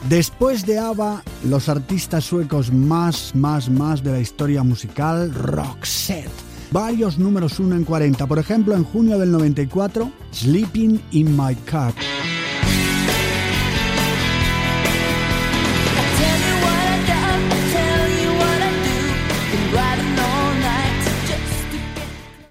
Presentació d'un tema musical.
Musical